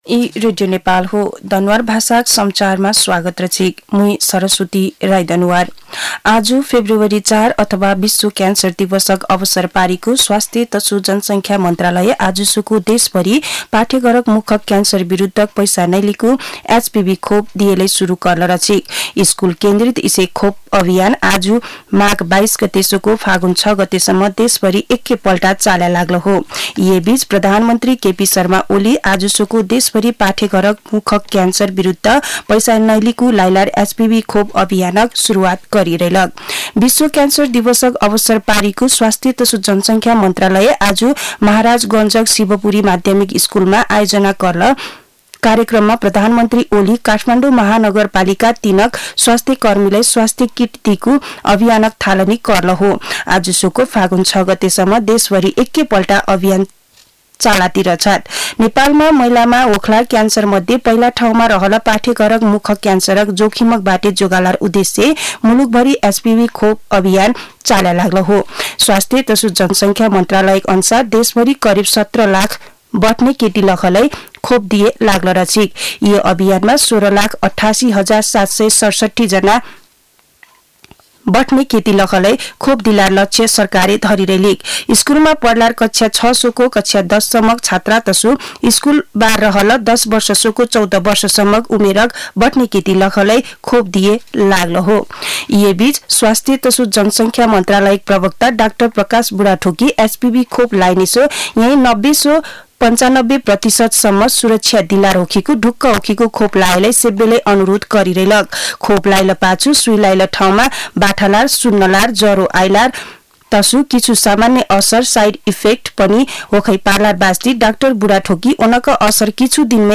दनुवार भाषामा समाचार : २३ माघ , २०८१
Danuwar-news.mp3